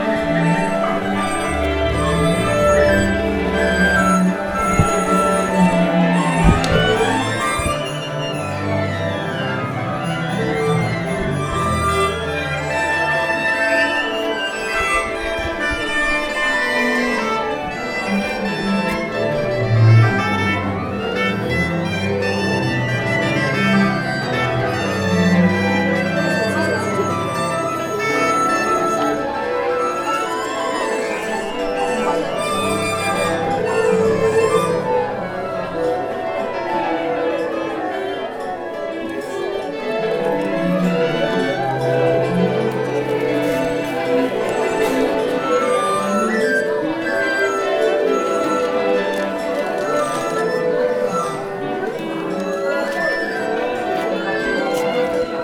Warming_up_Tunning
chinese indoor instruments Macau mandarin-house orchestra people tunning sound effect free sound royalty free Music